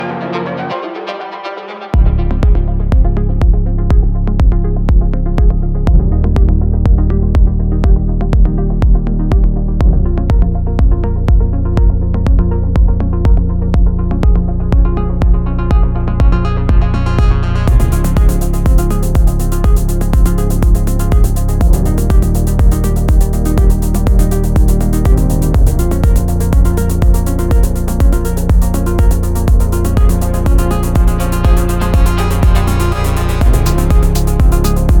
Жанр: Танцевальные
# Dance